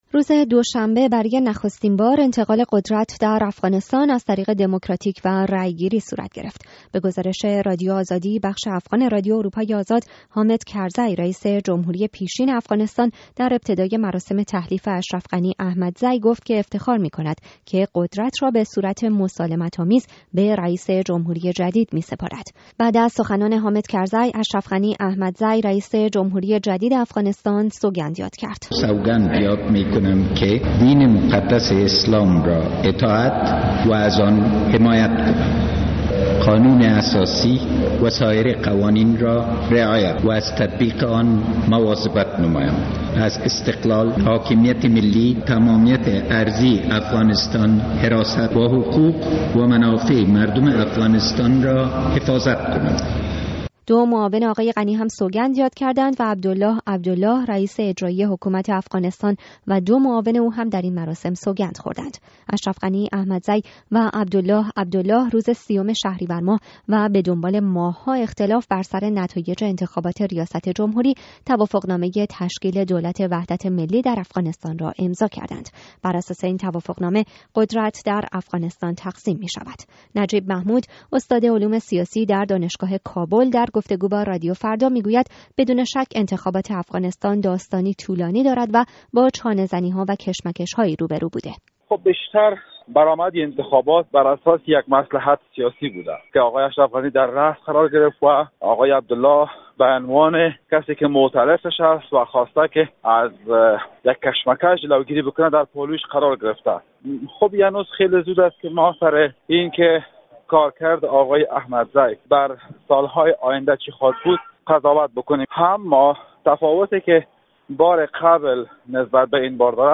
گزارش رادیو فردا از مراسم تحلیف و سوگند ریاست جمهوری در افغانستان